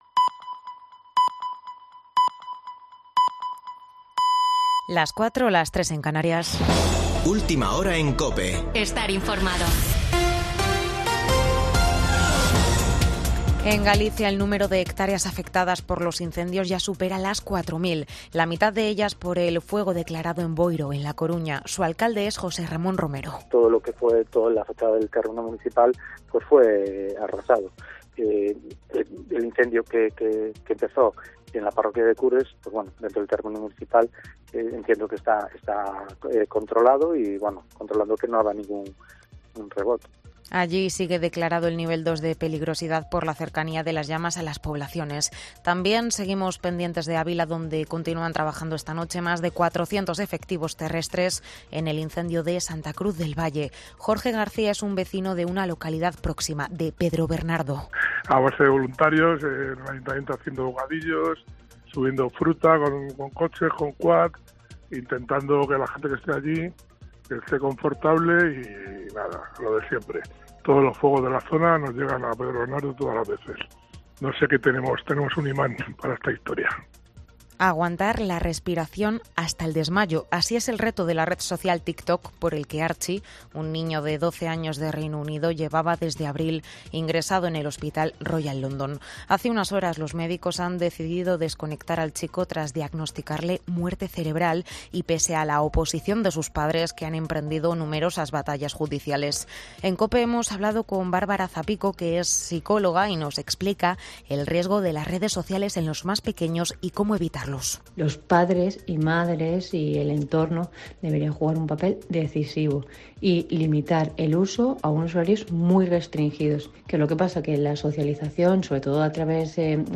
Boletín de noticias de COPE del 7 de agosto de 2022 a las 04.00 horas